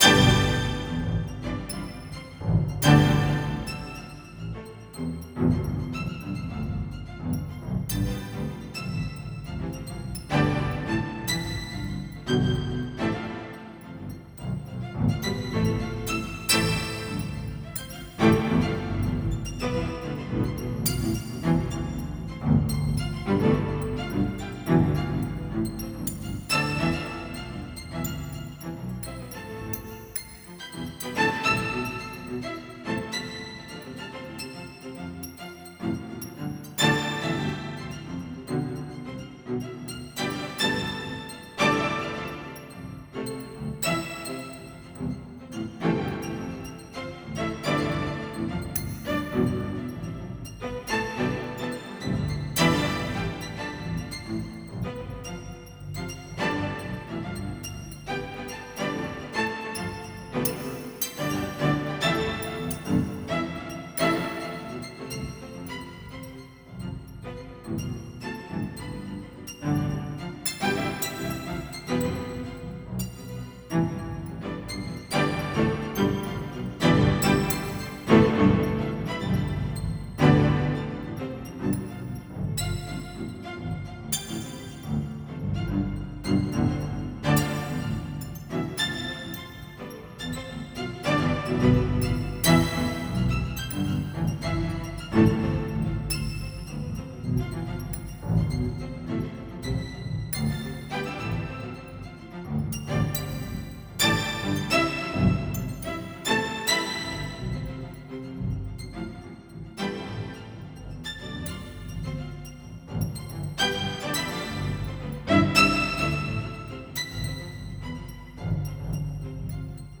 まさに、天使達が奏でる天国のストリングス・オーケストラっていう感じです。
気持ちよくなって、上昇感を持ちながら酩酊し、空気まで良い匂いに染まっていく様な、そんなアルバム。